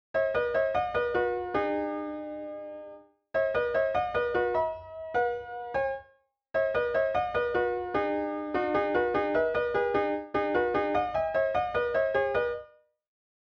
A transcription of the flute melody